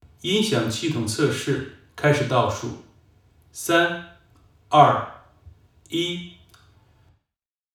Description:  Large recording studio (52,000 ft³) at university.
With a T30 of about 0.6 sec, this room is not statistically reverberant, but it is live and diffuse.
All in all, this is an extraordinary sounding space.
Speech_Track.wav